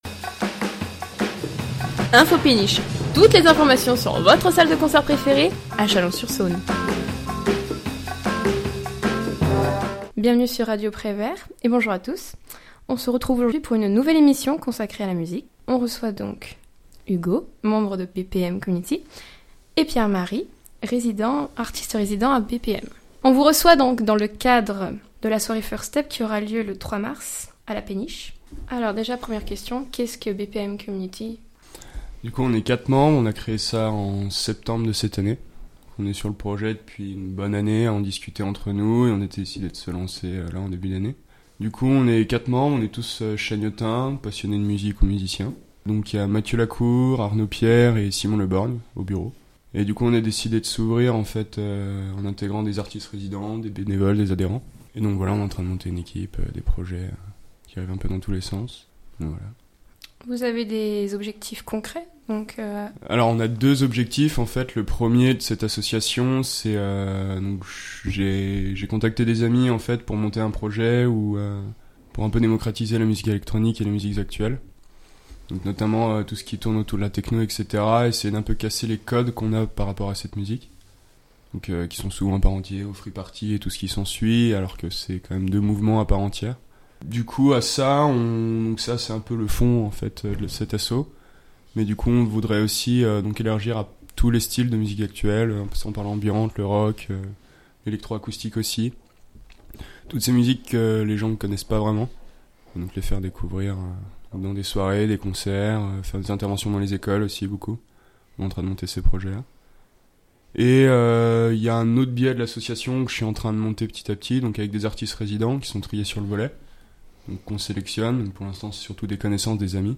Une interview à retrouver sur l'antenne de Radio Prévert et en ré-écoute sur le site internet.